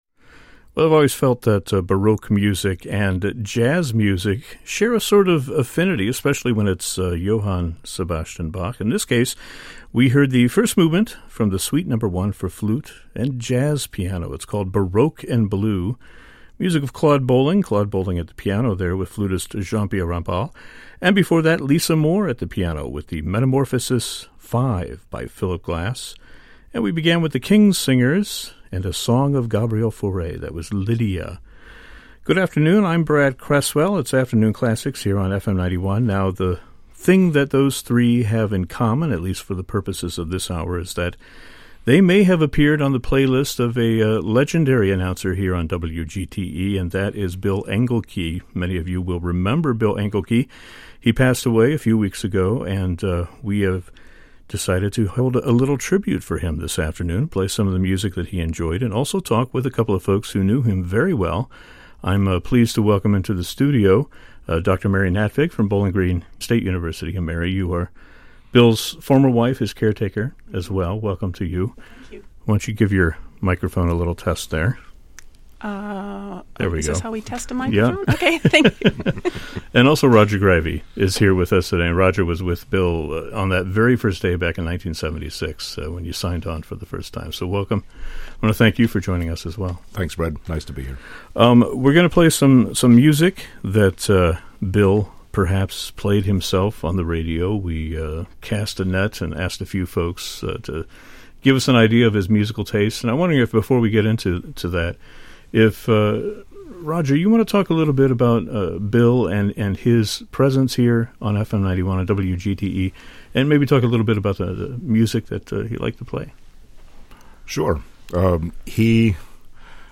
Note: musical selections are truncated due to copyright.